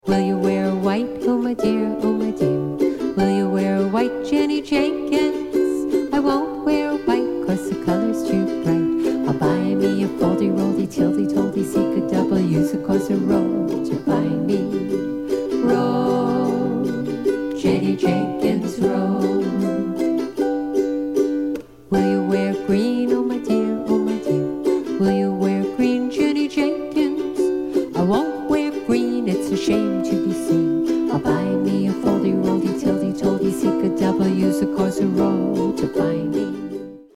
with the gentle simplicity of a traditional favorite